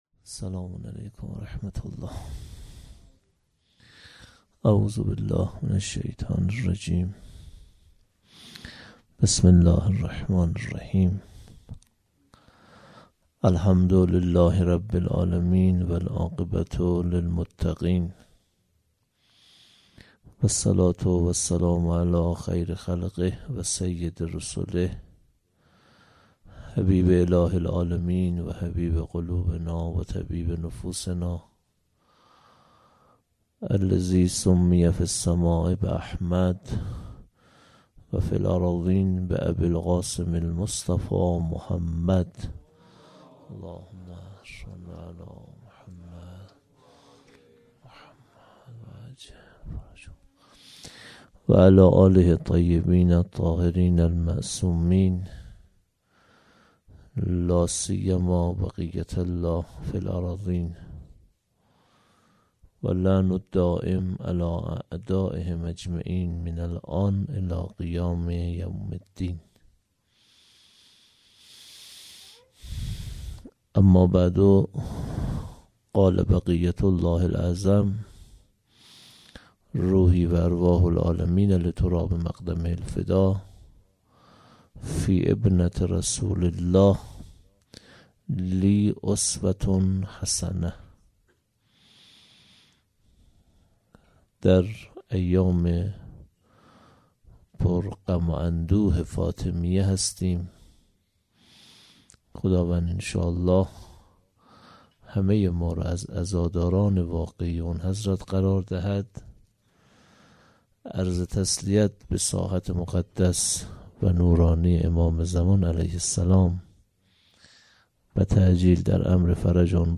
1-sokhanrani-shab1
1-sokhanrani.mp3